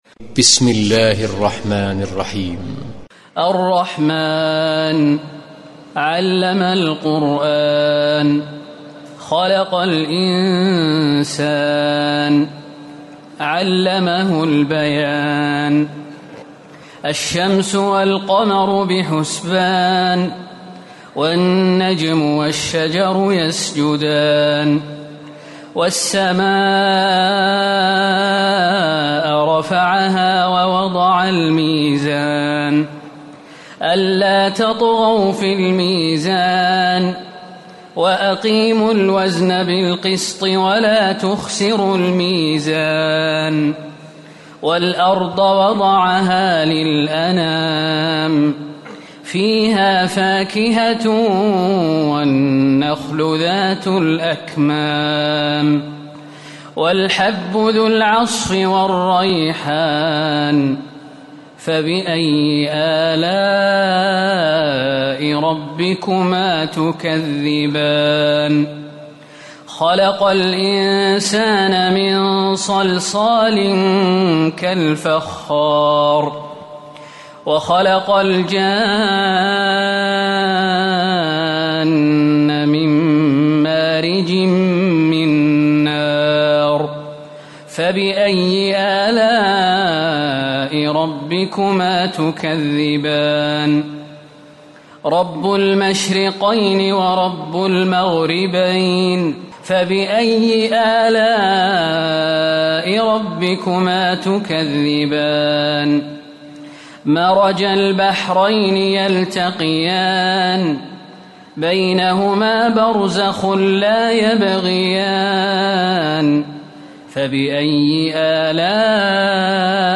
تراويح ليلة 26 رمضان 1437هـ من سور الرحمن الواقعة و الحديد Taraweeh 26 st night Ramadan 1437H from Surah Ar-Rahmaan and Al-Waaqia and Al-Hadid > تراويح الحرم النبوي عام 1437 🕌 > التراويح - تلاوات الحرمين